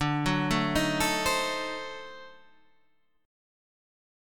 D7b9 chord